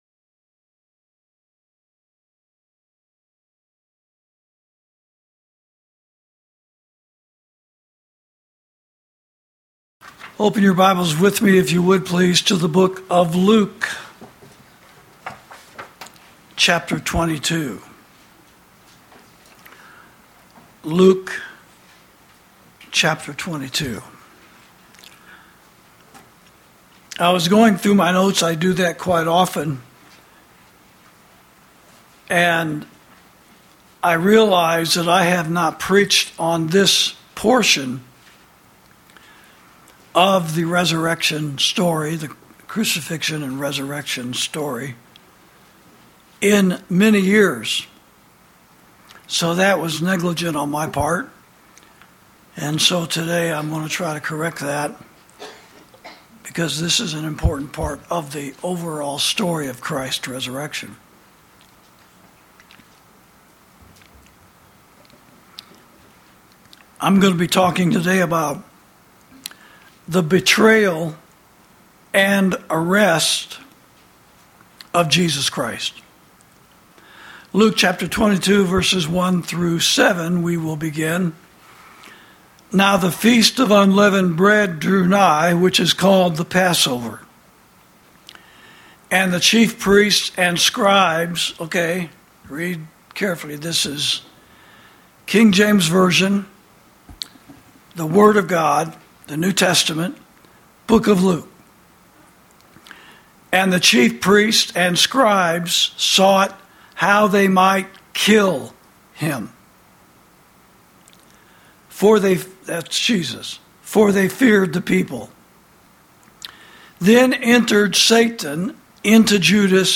Sermons > The Betrayal And Arrest Of Jesus Christ